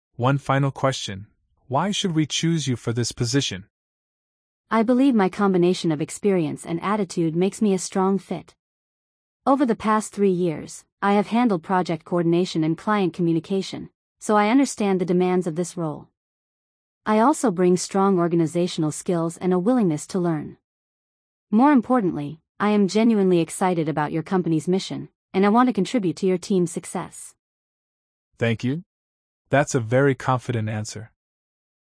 🤝 The interviewer asks the final, toughest question.